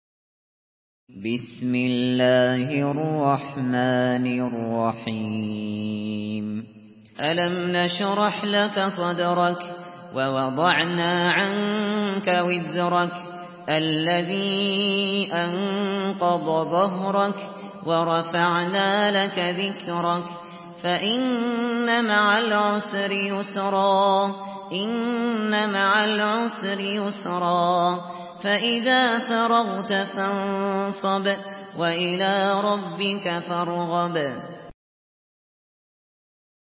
Surah الشرح MP3 in the Voice of أبو بكر الشاطري in حفص Narration
مرتل